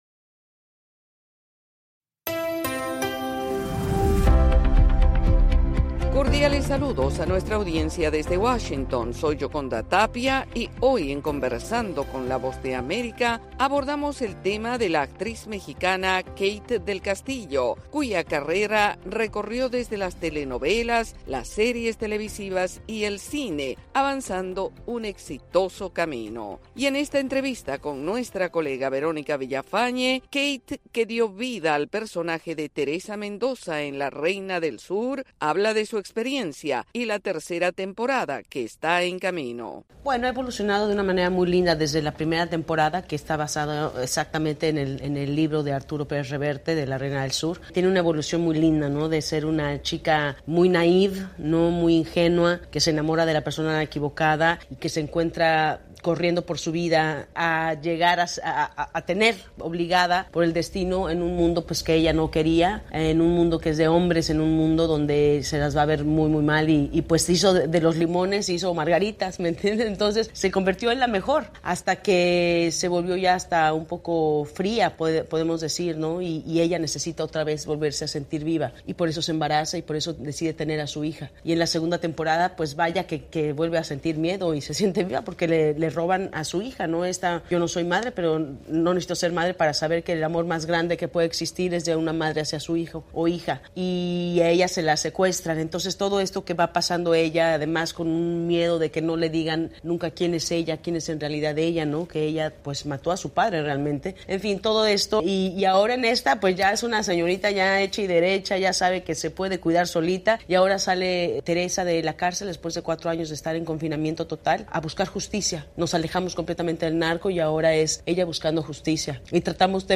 Conversamos la actriz mexicana Kate del Castillo, principal protagonista de la serie televisiva La Reina del Sur, hablando de su carrera y sus desafíos.